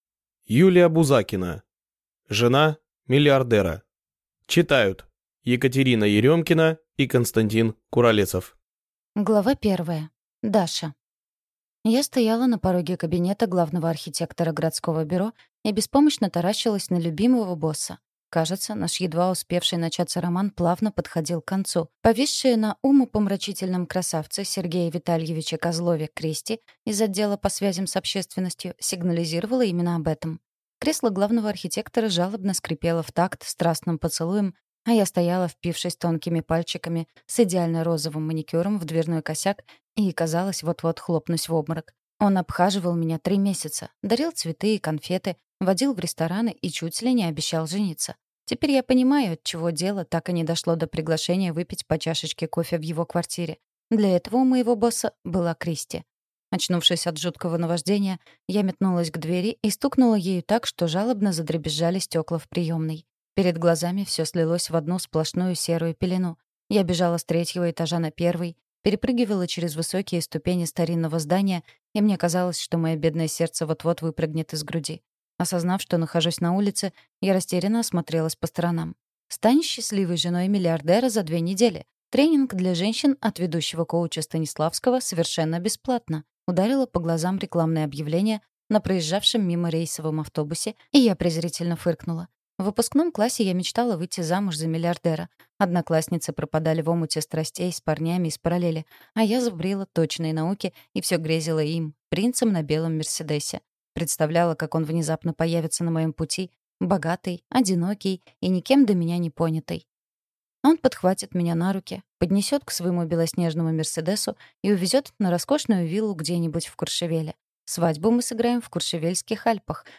Аудиокнига Жена миллиардера | Библиотека аудиокниг